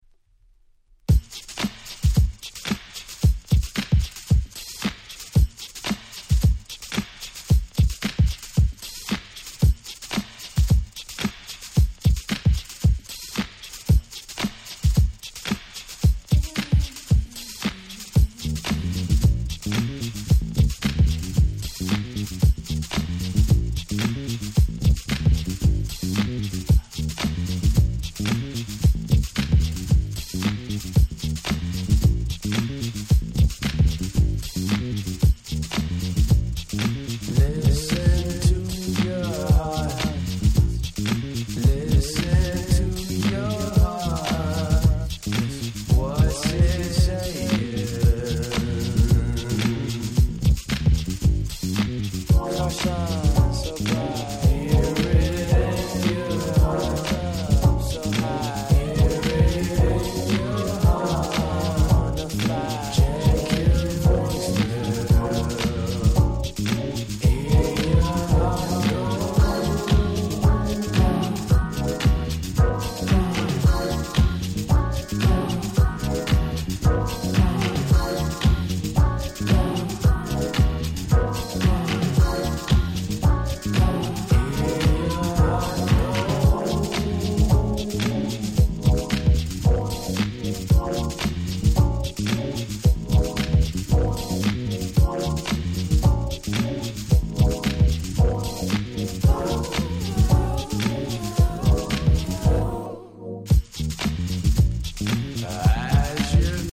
【Media】Vinyl 2LP
18' Smash Hit R&B/Neo Soul LP !!